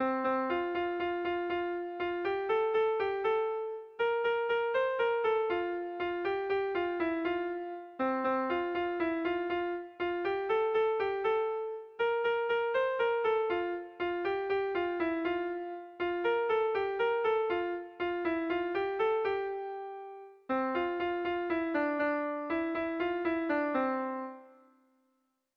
Sentimenduzkoa
Hamabiko txikia (hg) / Sei puntuko txikia (ip)
ABABDE